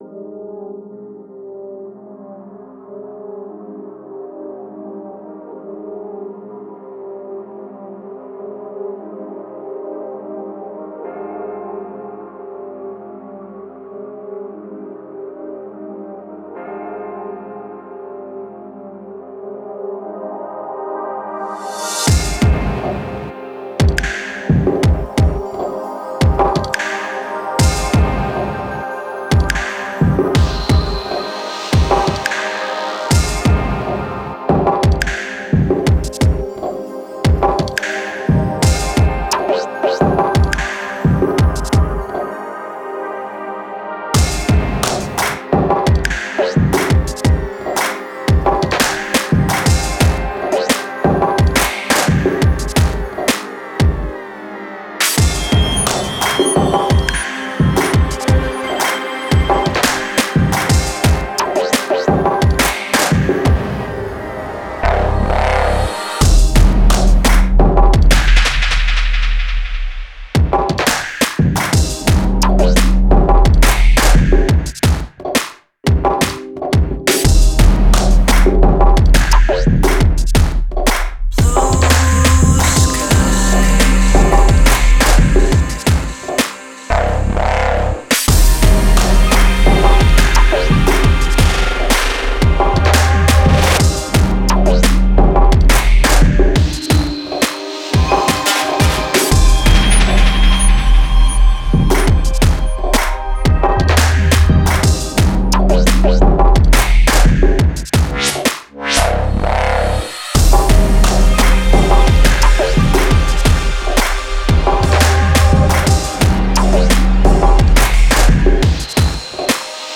Style: Drum & Bass